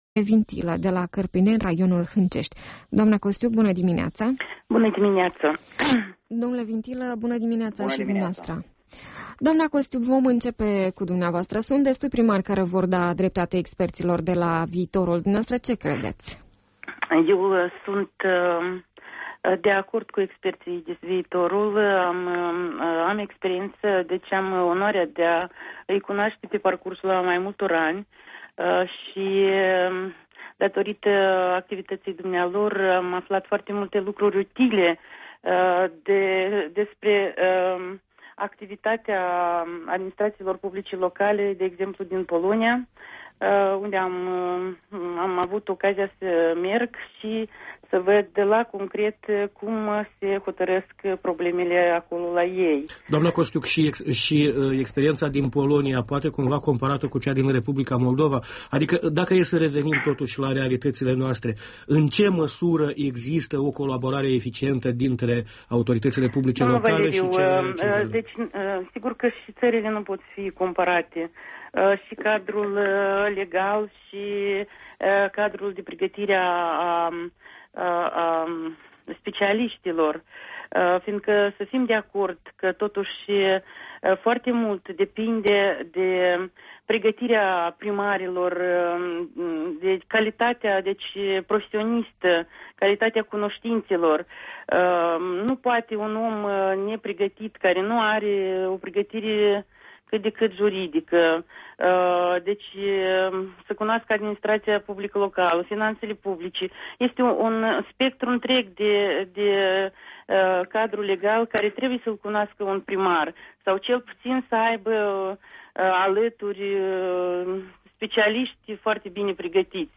Interviu cu primarii Nina Costiuc din Budești și Iurie Vintilă din Cărpineni (Hîncești)